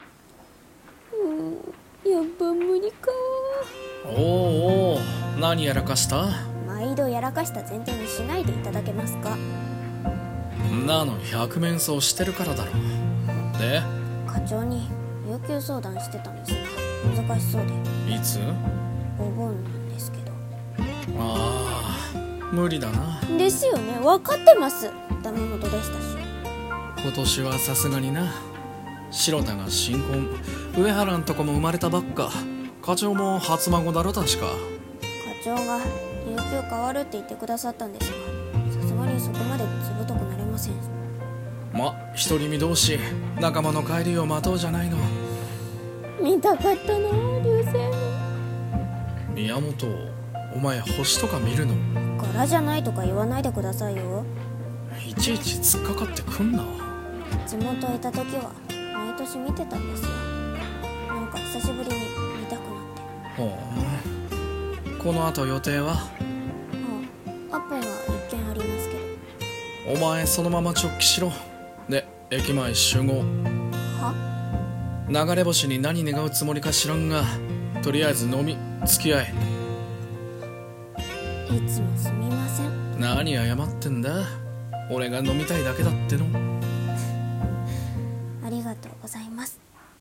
【2人声劇】星への願いは居酒屋で。